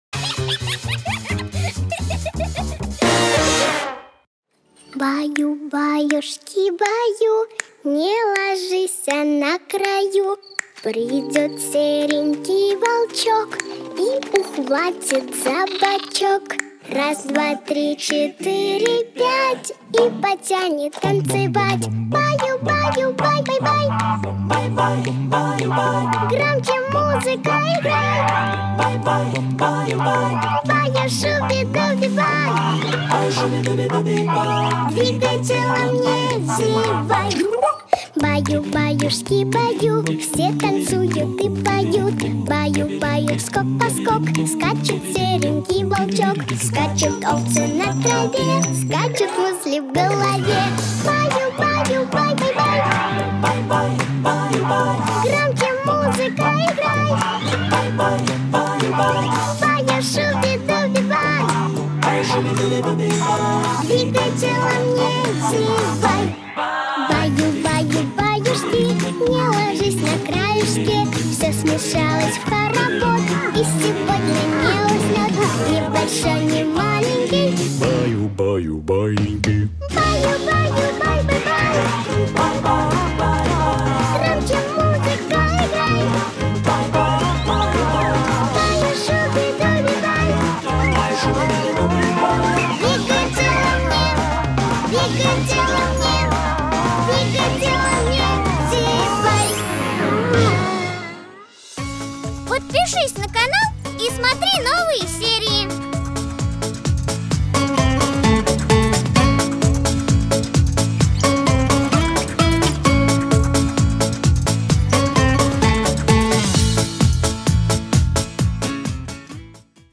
Лучшие детские песенки Слушали